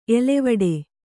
♪ elevaḍe